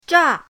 zha4.mp3